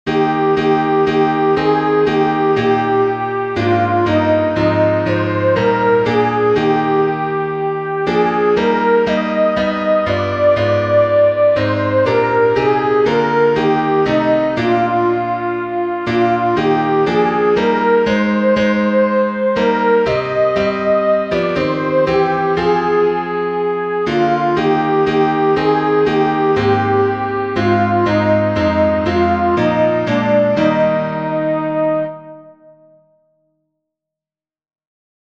aurelia_the_churchs_one_foundation-soprano.mp3